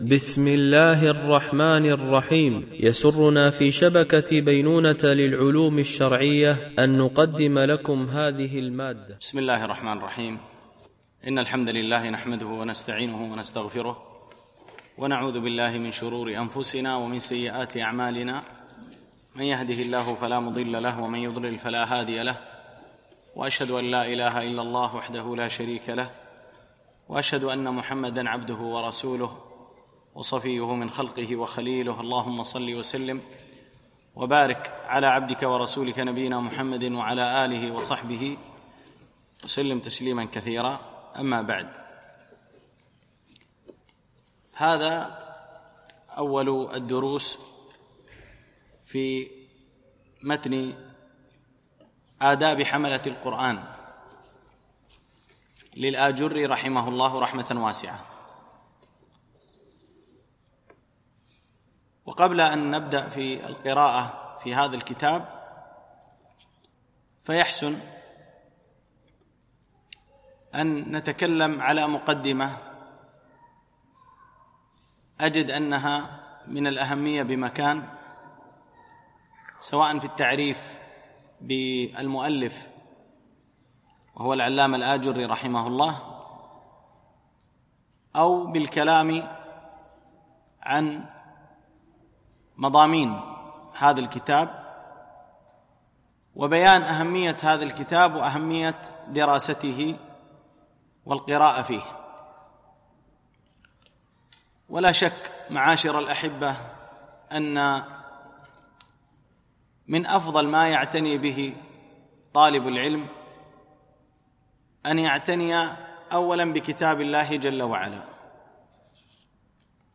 شرح آداب حملة القرآن ـ الدرس 1